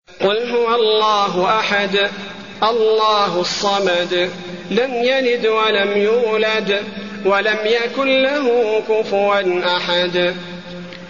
المكان: المسجد النبوي الإخلاص